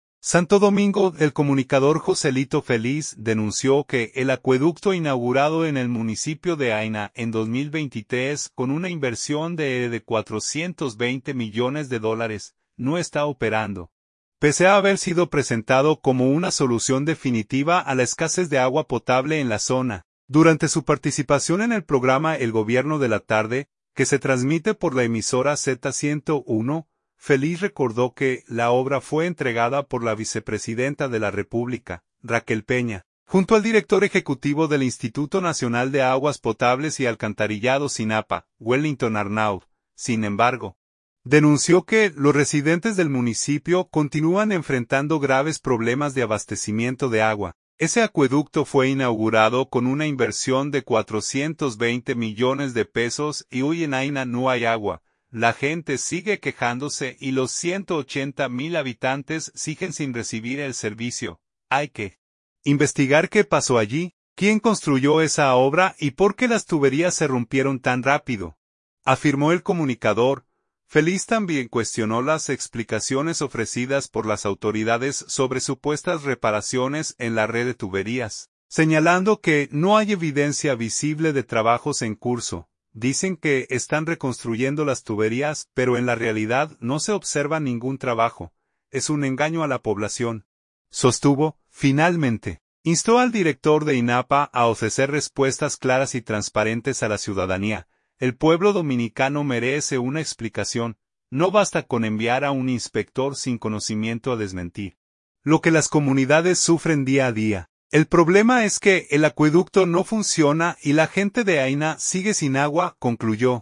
Durante su participación en el programa "El Gobierno de la Tarde", que se transmite por la emisora Z101